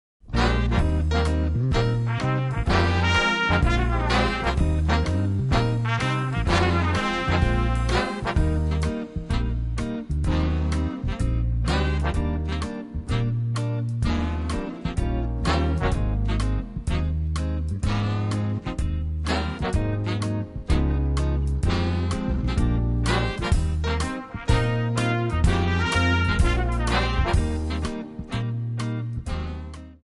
Eb
Backing track Karaoke
Pop, Oldies, Jazz/Big Band, 1950s